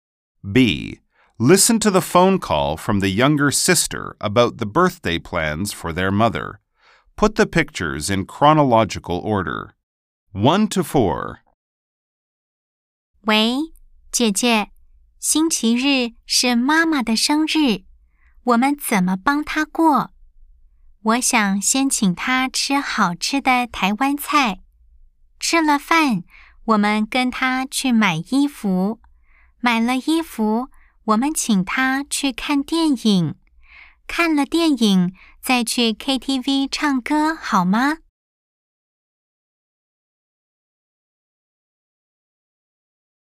B. Nghe cuộc điện thoại của cô em gái về kế hoạch tổ chức sinh nhật cho mẹ 🎧 (13-4)